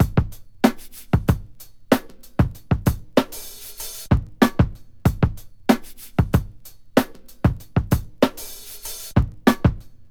• 95 Bpm Drum Groove E Key.wav
Free drum loop - kick tuned to the E note. Loudest frequency: 1187Hz
95-bpm-drum-groove-e-key-yQs.wav